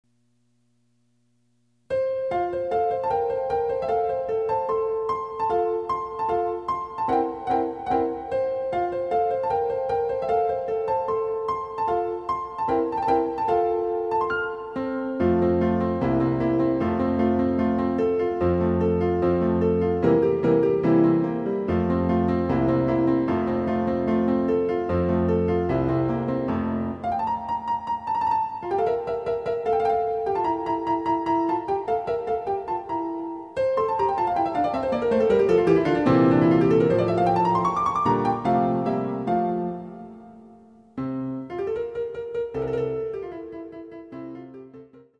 ★フルートの名曲をピアノ伴奏つきで演奏できる、「ピアノ伴奏ＣＤつき楽譜」です。
試聴ファイル（伴奏）